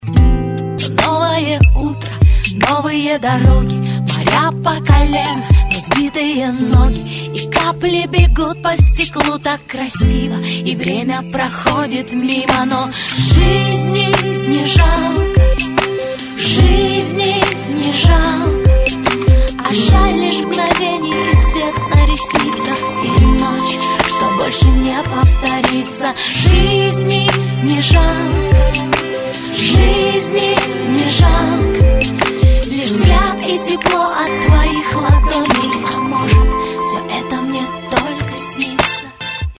Rap, RnB, Hip-Hop